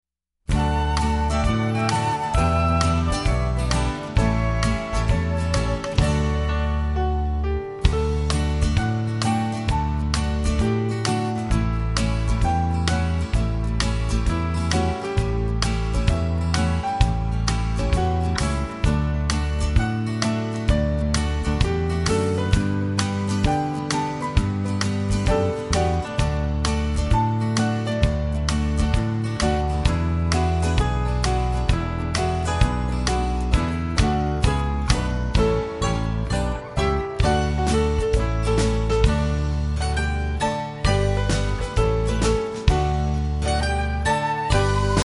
Listen to a sample of the instrumental.